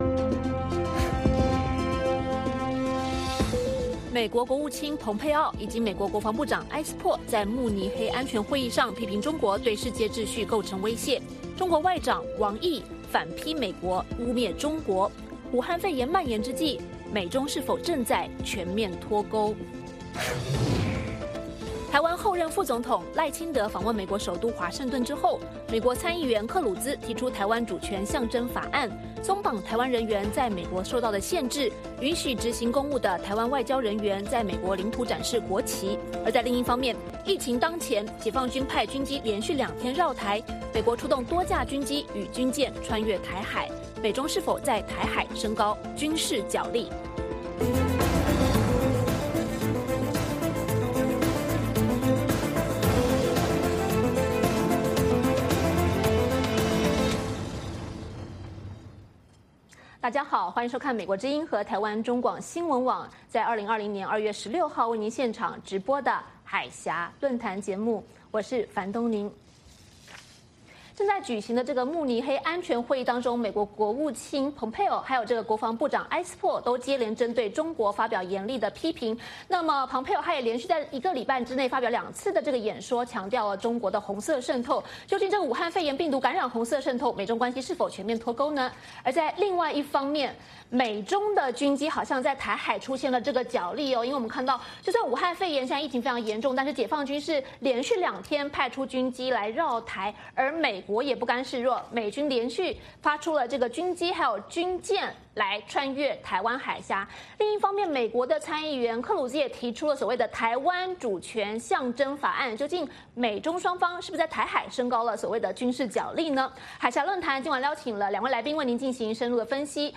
美国之音中文广播于北京时间每周日晚上9-10点播出《海峡论谈》节目(电视、广播同步播出)。《海峡论谈》节目邀请华盛顿和台北专家学者现场讨论政治、经济等各种两岸最新热门话题。